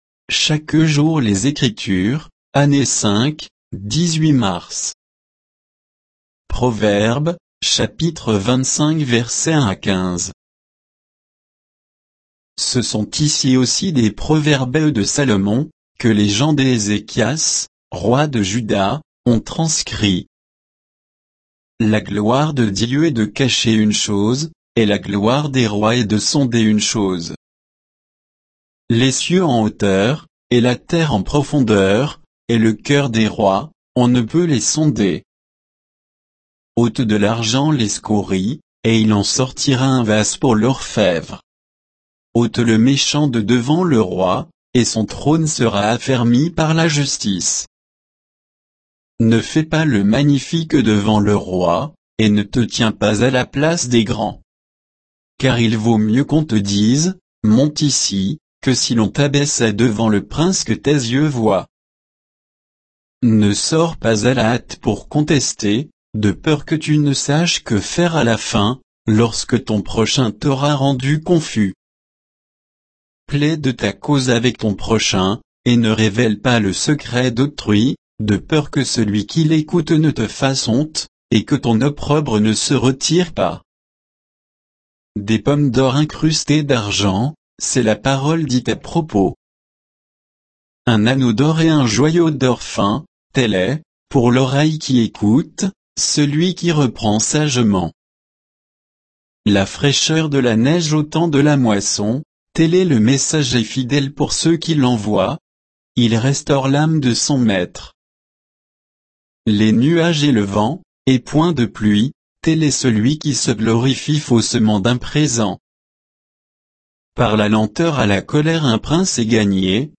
Méditation quoditienne de Chaque jour les Écritures sur Proverbes 25, 1 à 15